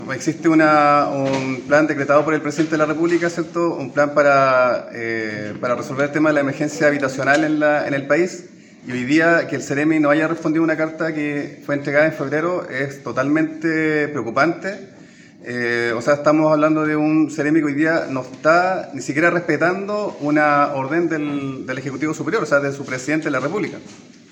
El Core Cristian Vargas, calificó la situación de preocupante y solicitó a la autoridad regional de vivienda hacer el trabajo que le ha sido mandatado.